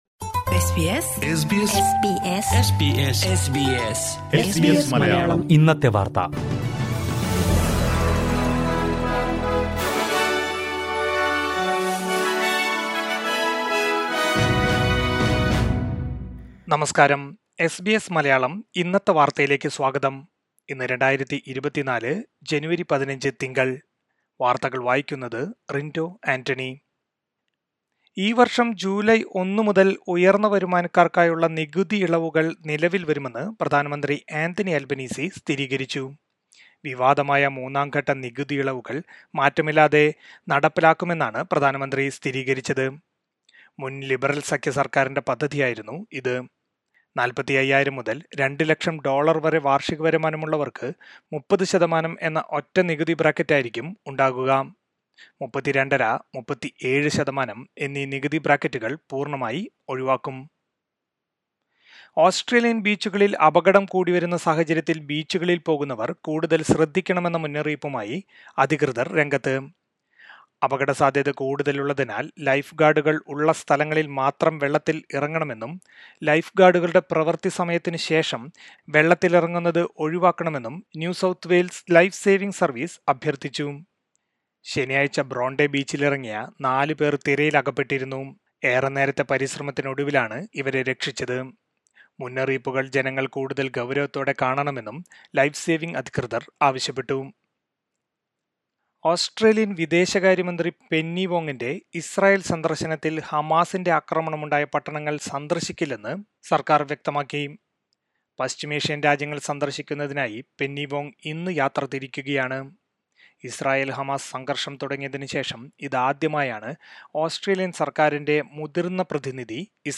2024 ജനുവരി 15 ലെ ഓസ്‌ട്രേലിയയിലെ ഏറ്റവും പ്രധാന വാര്‍ത്തകള്‍ കേള്‍ക്കാം...